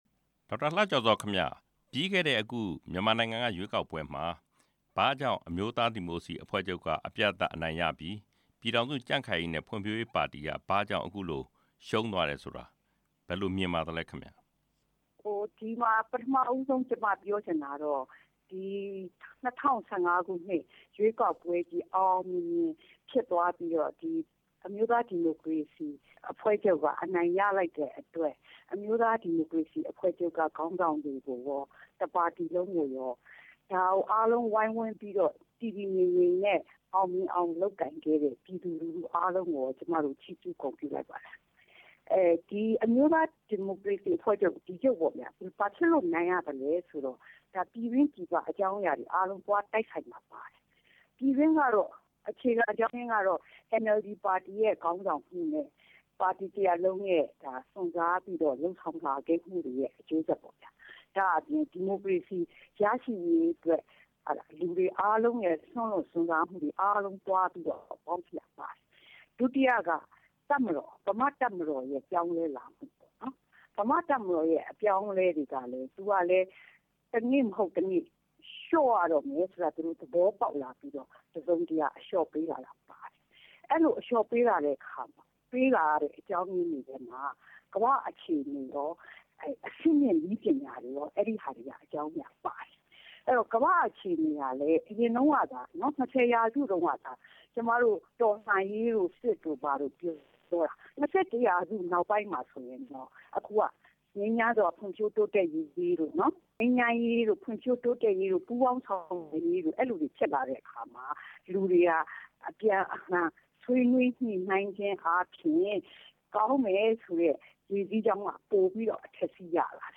ရွေးကောက်ပွဲ နောက်ဆက်တွဲအခြေအနေအကြောင်း မေးမြန်းချက်